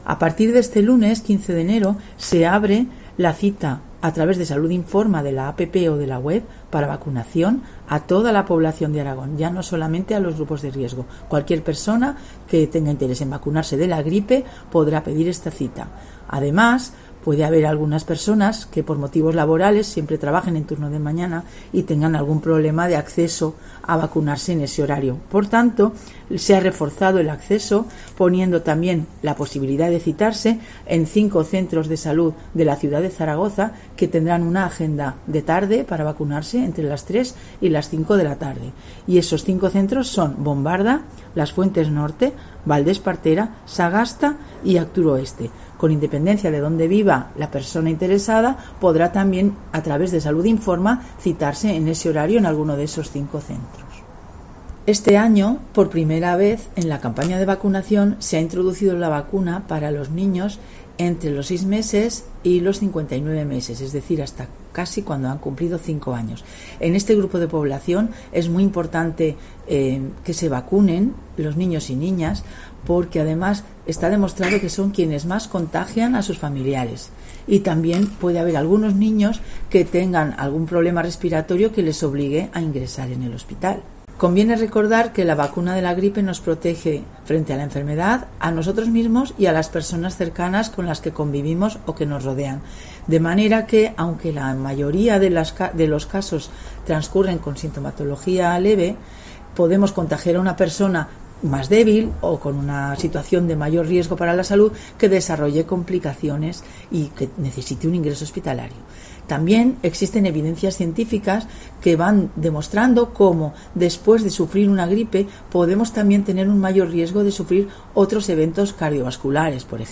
La directora general de Salud Pública, Nuria Gayán, sobre la vacunación general contra la gripe